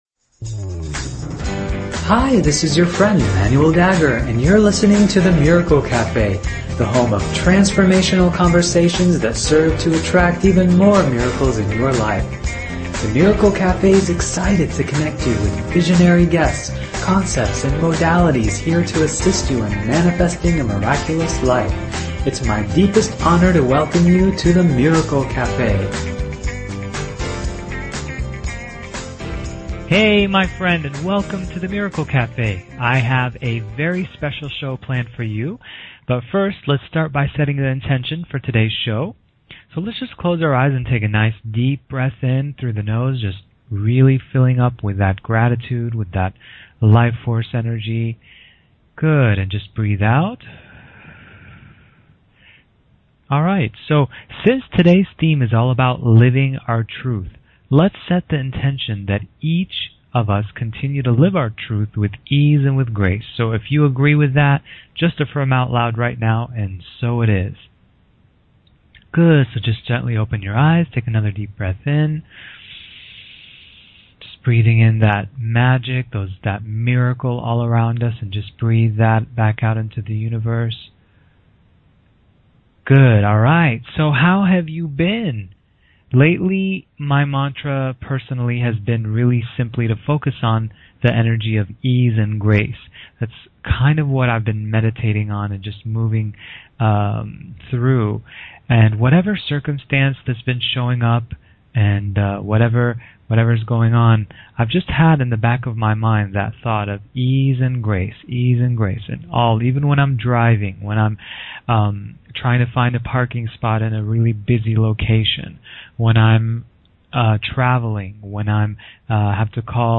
The Miracle Café is a radio show that is here to provide each listener with life-changing insights, processes, and conversations that serve to attract even more miracles and positive transformation in their life!